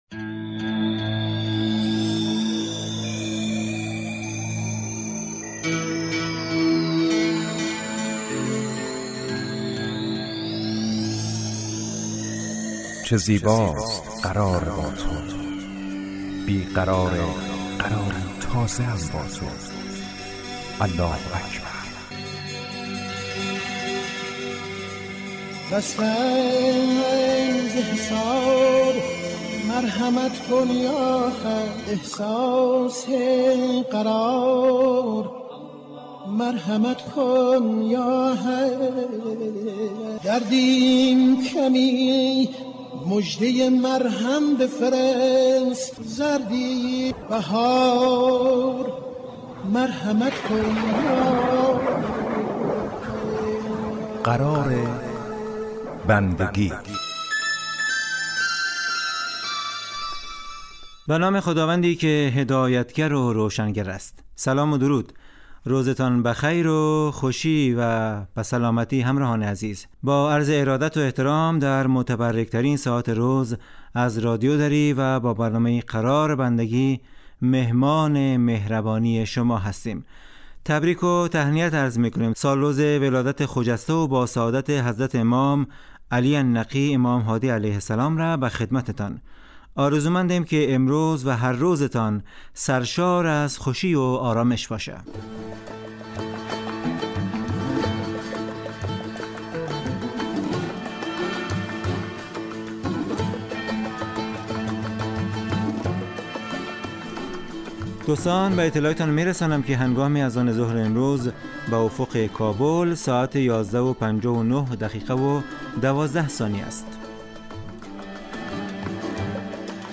قرار بندگی برنامه اذانگاهی در 30 دقیقه هر روز ظهر پخش می شود.